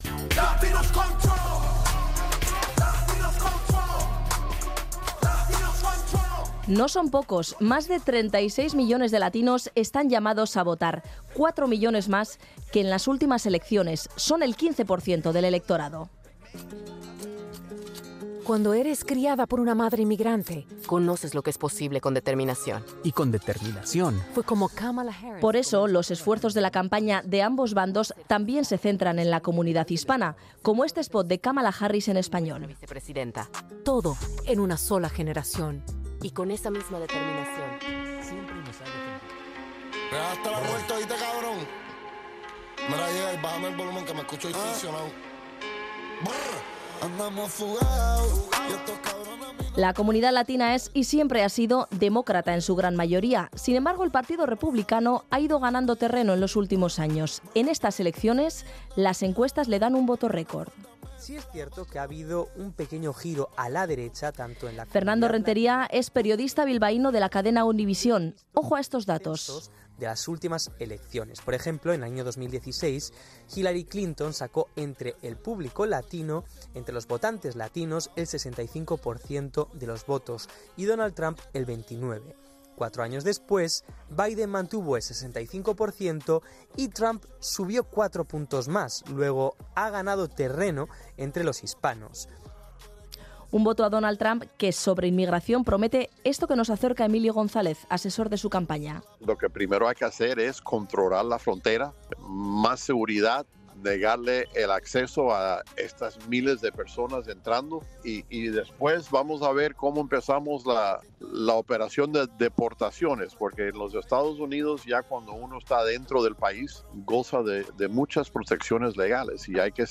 Perreando y Rapeando | Erreportajeak